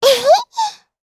Taily-Vox_Happy1_jp.wav